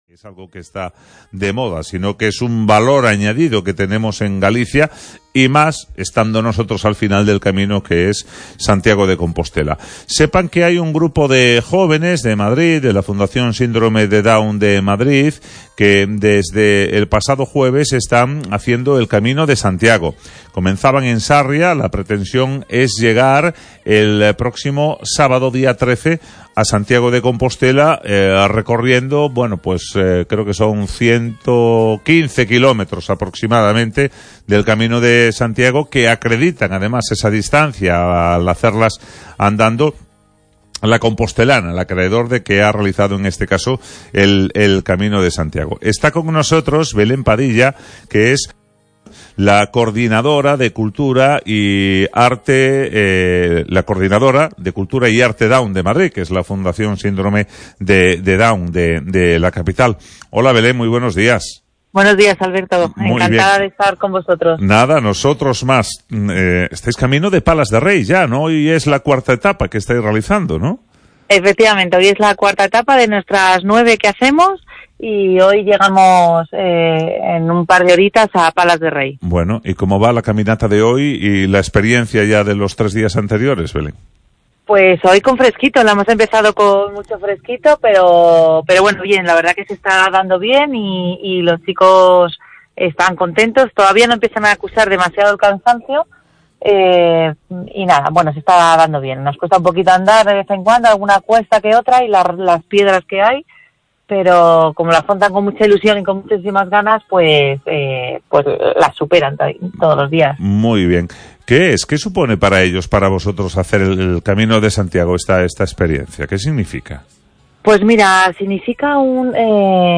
Down Madrid » Entrevista